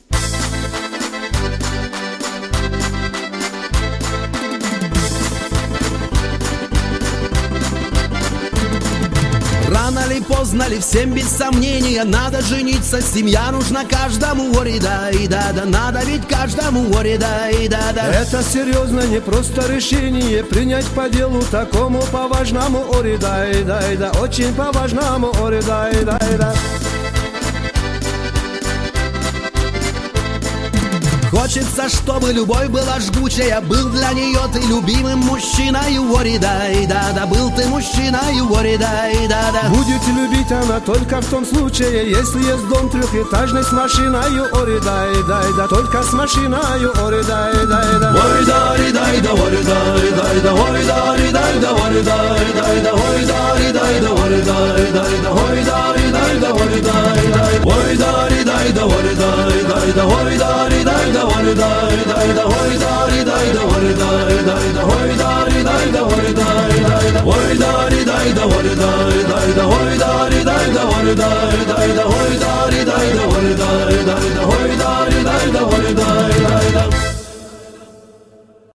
кавказские